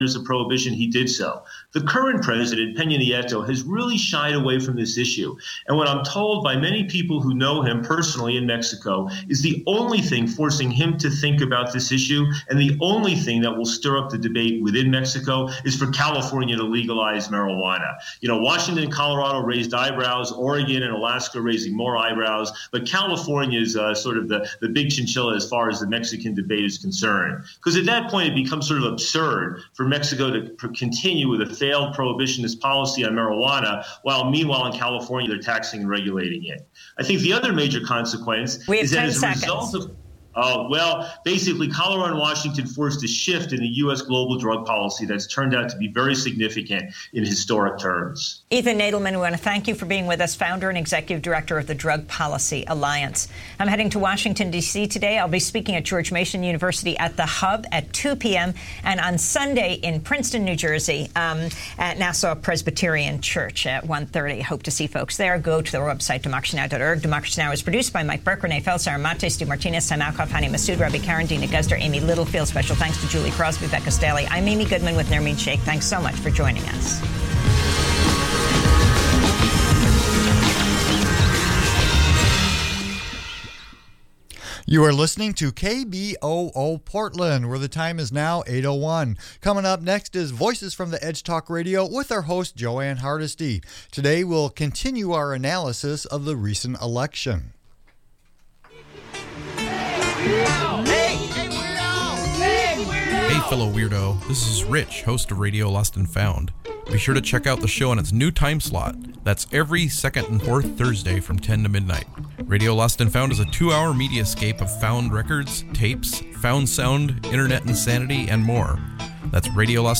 Progressive talk radio from a grassroots perspective
With an hour to invest, the call-in format engages listeners in meaningful conversations about crucial issues like racial disparity, government accountability, environmental justice and politics on local, state and national levels.